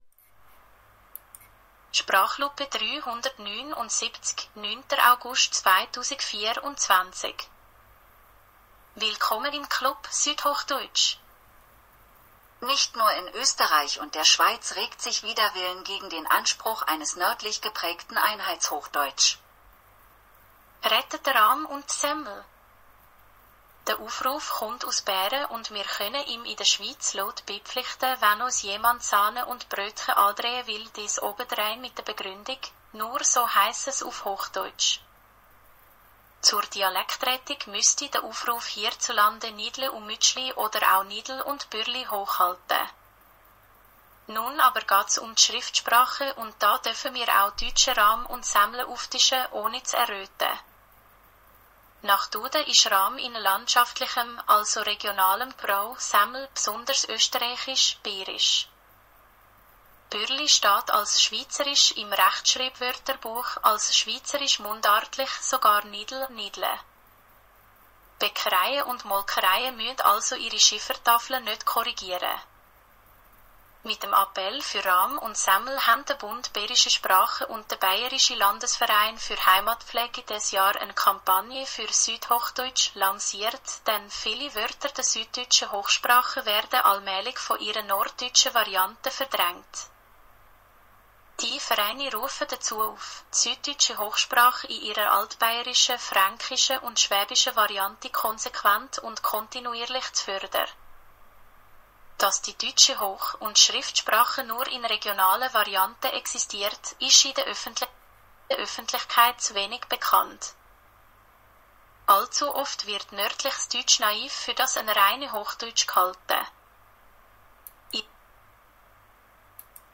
Aus Microsofts Datenwolke erklingt eine fehlgeleitete Stimme – wenn man den richtigen Knopf findet.
Es klingt nach Mundart, ist aber noch bei Weitem keine.
Schweizerische Ohren bekommen einen Graus zu hören, uneingeweihte immerhin einen mundartlichen Klang, aber Wort für Wort auf den Text appliziert. Bei gängigen Wörtern kommt tatsächlich die zürcherische Dialekt-Entsprechung, sonst ungefähres Hochdeutsch mit Akzent und immer gemäss der Grammatik der Vorlage, also auch mit Genitiv- und Präteritumformen, die es in keiner Deutschschweizer Mundart gibt.